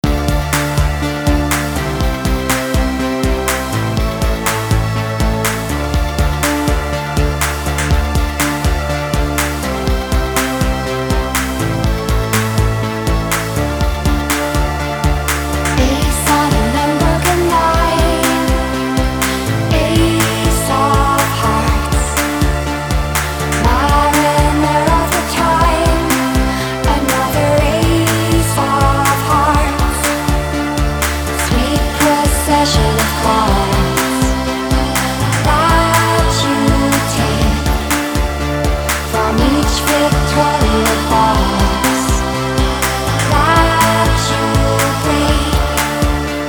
Synth Pop
электронная музыка
спокойные
baroque pop
Electropop